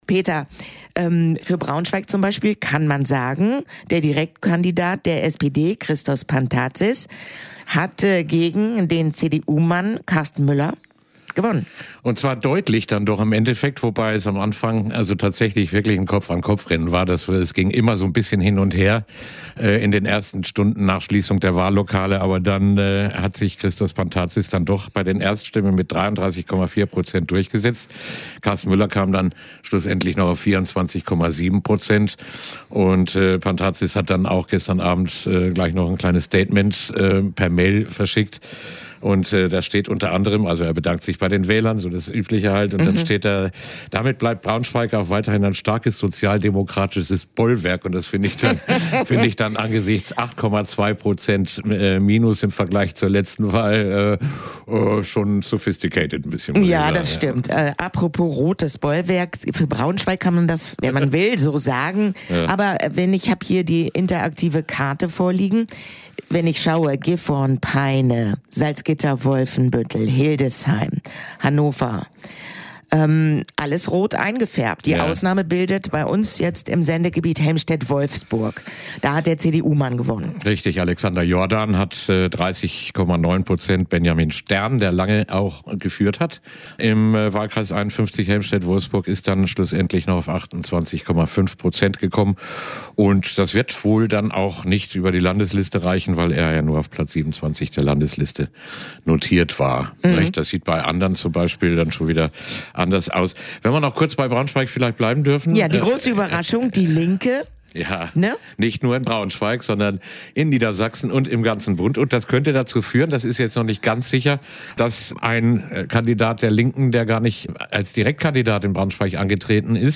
Interview-Wahl-Nachlese_nb-pmm.mp3